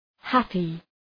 Προφορά
{‘hæpı}